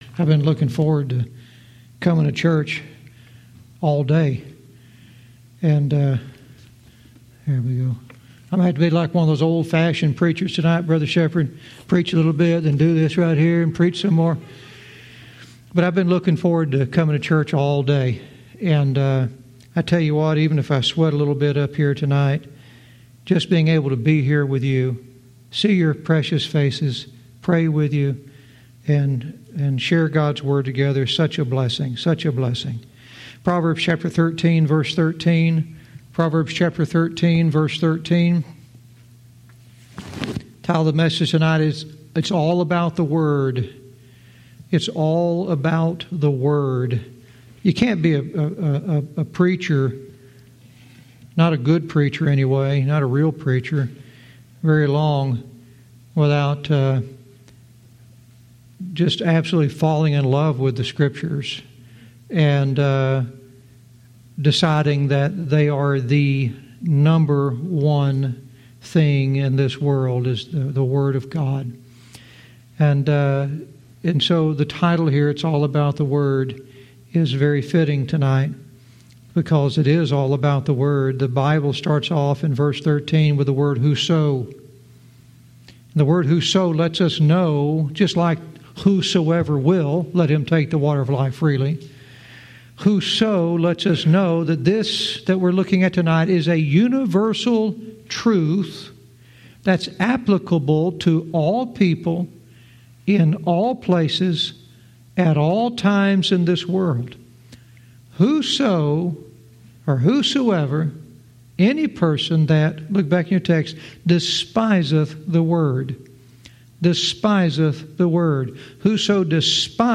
Verse by verse teaching - Proverbs 13:13 "It's all about the word"